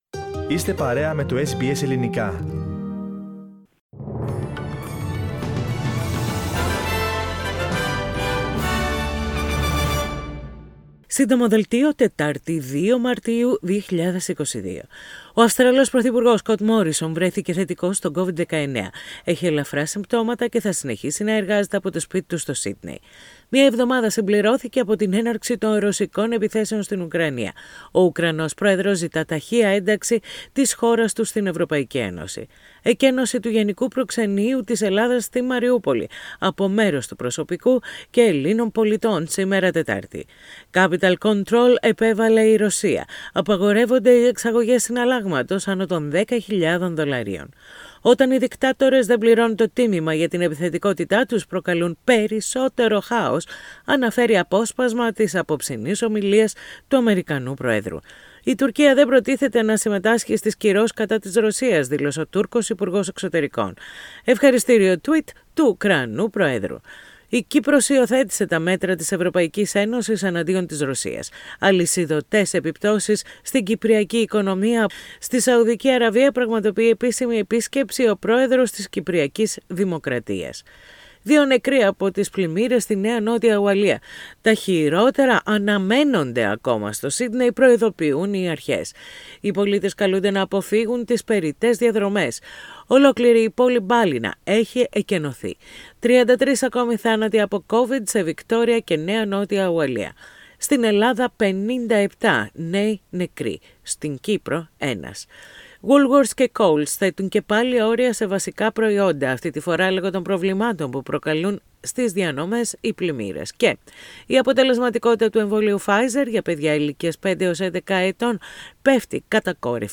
The short bulletin of the day with the headlines of the main news from Australia, Greece, Cyprus and the international arena.